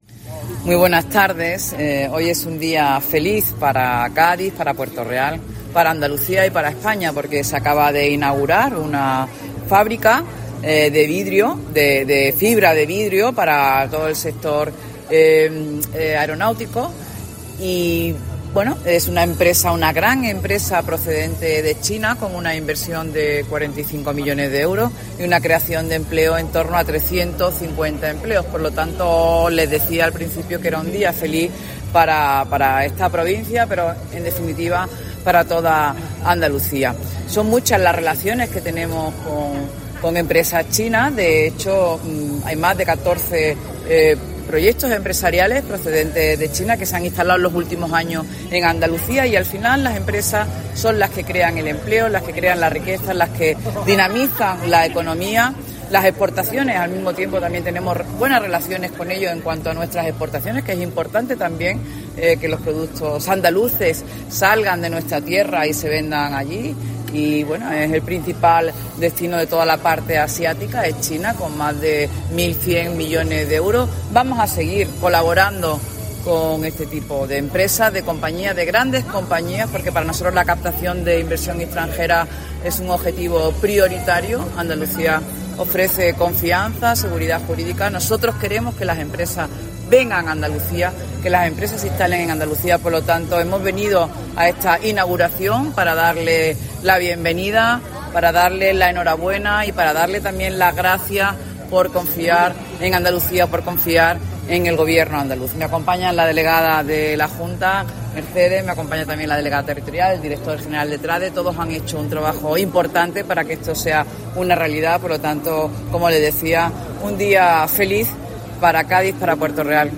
Palabras de la consejera de Hacienda, Carolina España, en la Inauguración de Zhenshi